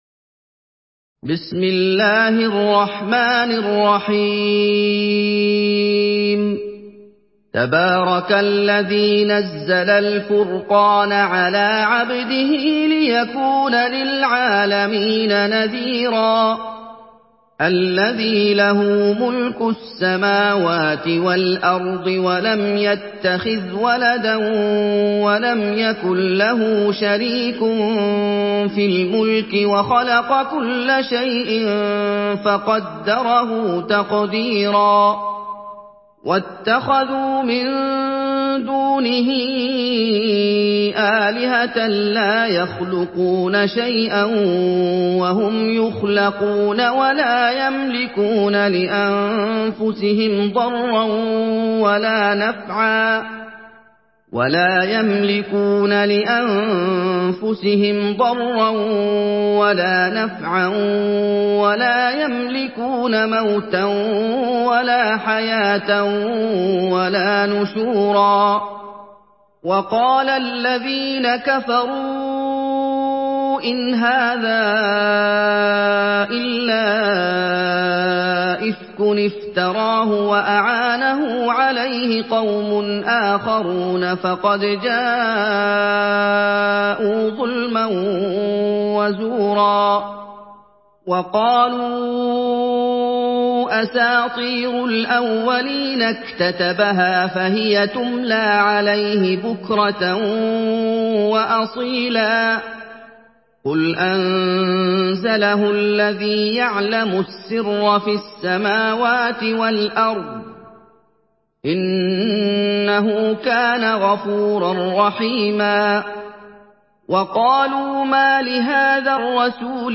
سورة الفرقان MP3 بصوت محمد أيوب برواية حفص
مرتل